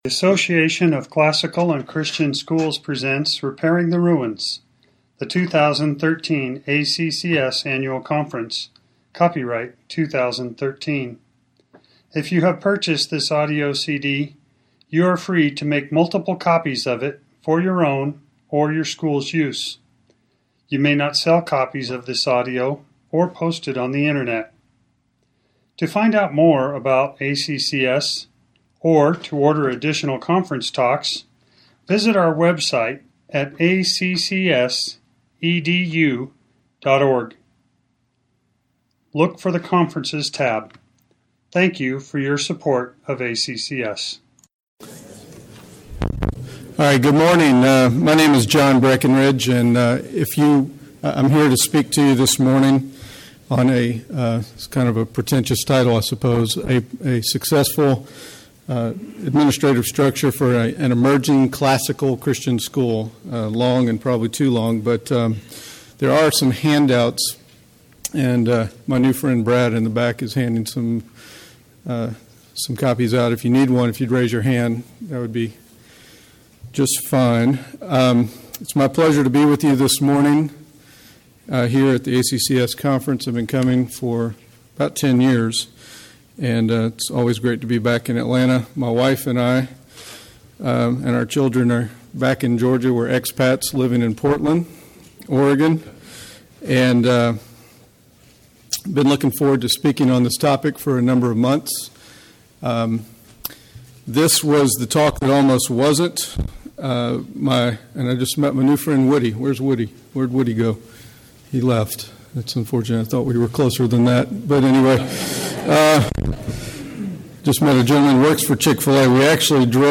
2013 Workshop Talk | 1:01:59 | Leadership & Strategic, Marketing & Growth
The Association of Classical & Christian Schools presents Repairing the Ruins, the ACCS annual conference, copyright ACCS.